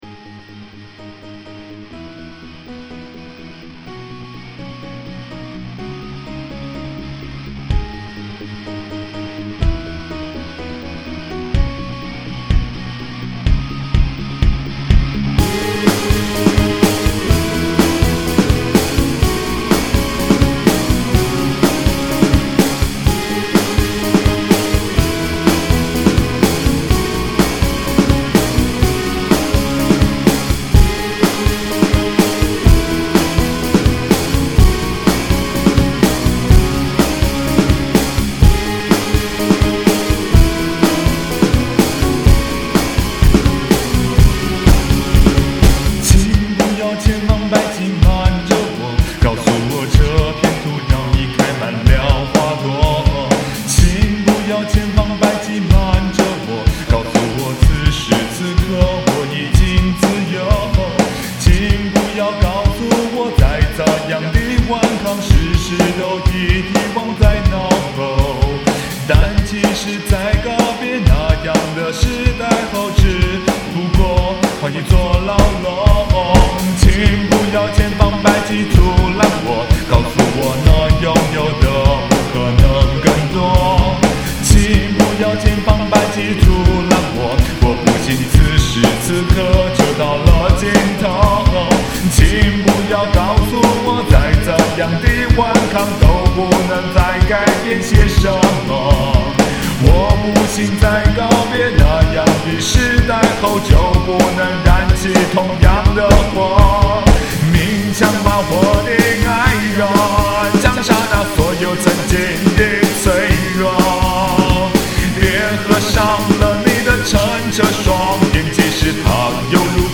昨天在GarageBand裡頭玩出來的。